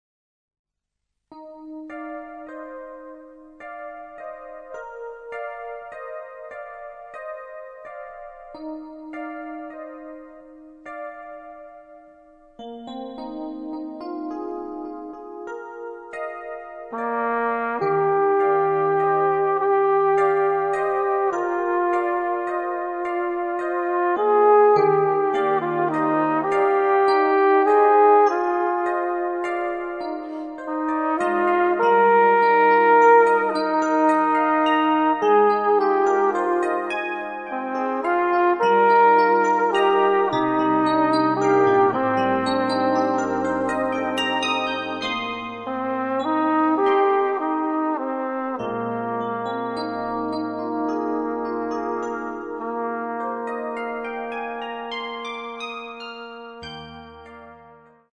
Piano elettrico
Flicorno e Piano elettrico Flügelhorn and electric piano